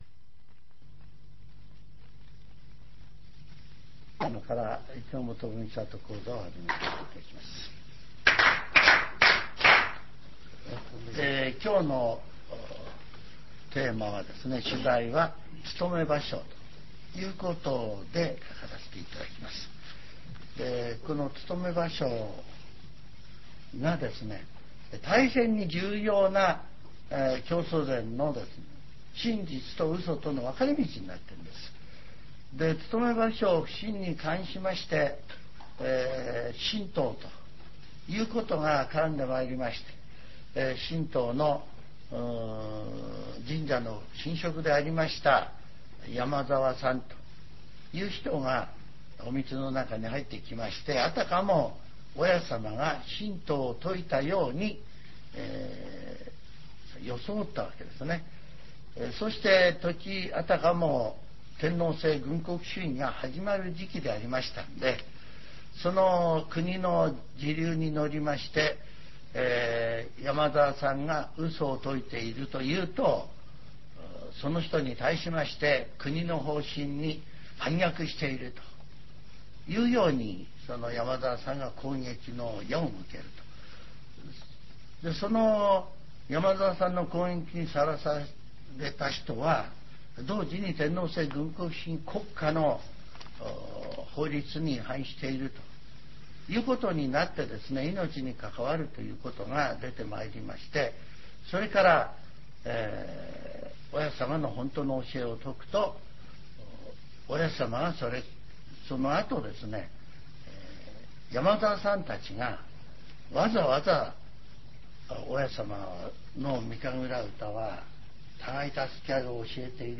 全70曲中20曲目 ジャンル: Speech